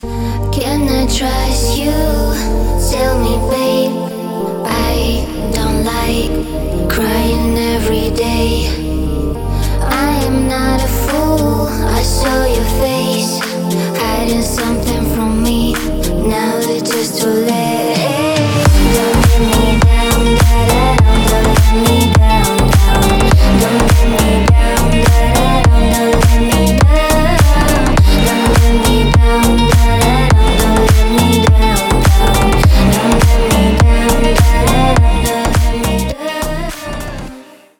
Танцевальные рингтоны
Громкие рингтоны